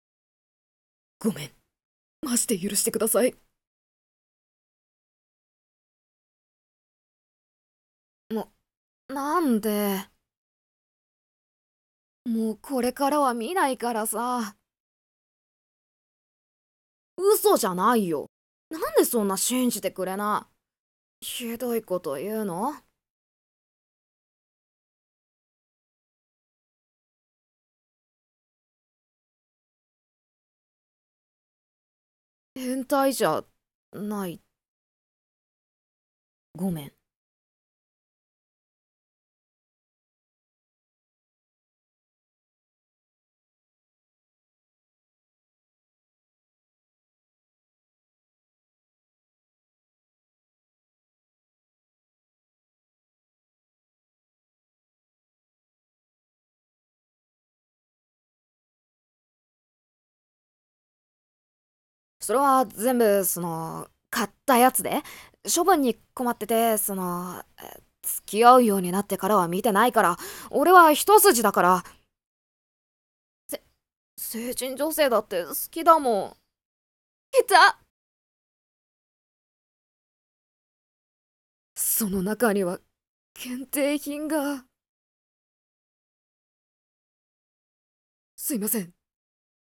2人用声劇